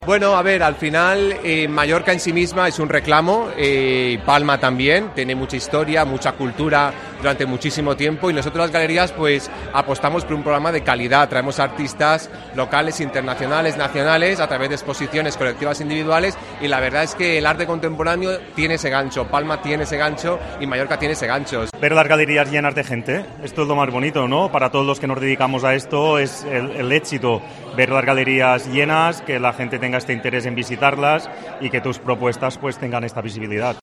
Declaracines